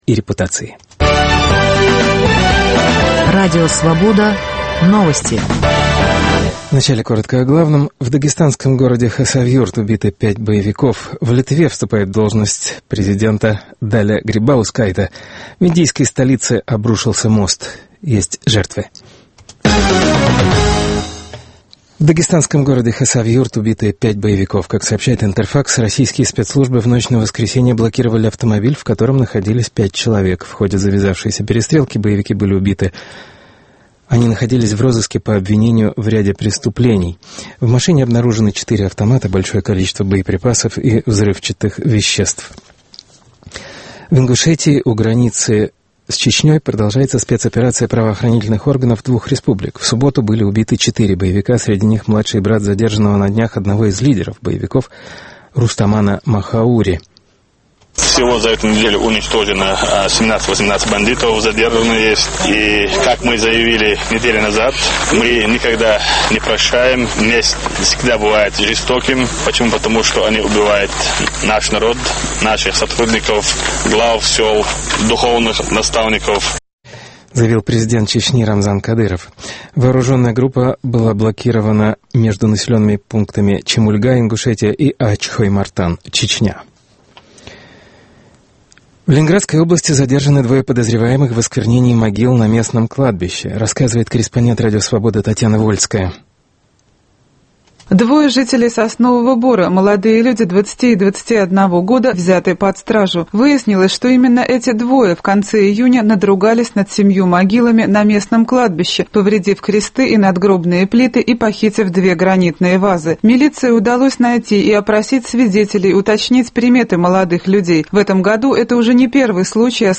Василий Аксенов у микрофона Свободы: памяти писателя, скончавшегося в Москве 6 июля.
Свободовский архив насчитывает 143 записи – интервью, чтения собственных книг, участие в круглых столах, радиоэссеистика. Мы предлагаем фрагменты записей начала 80-х, объединенных темой русской литературы.